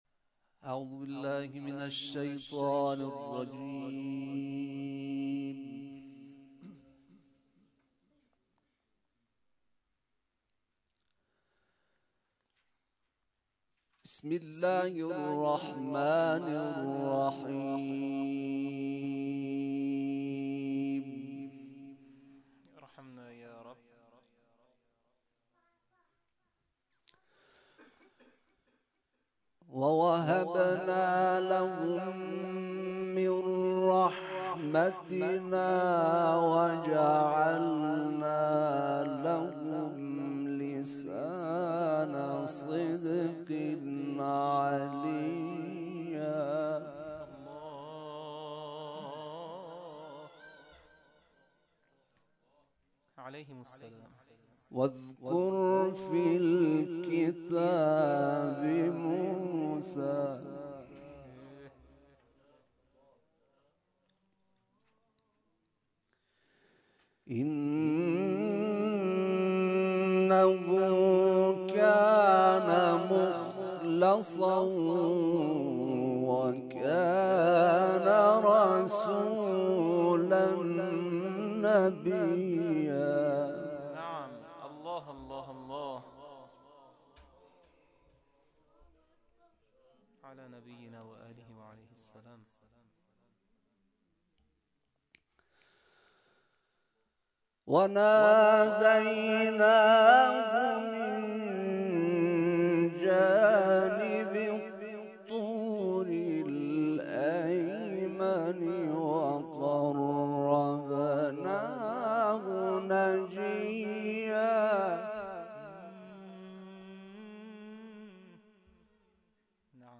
جلسه آموزش نفحات القرآن برگزار شد+صوت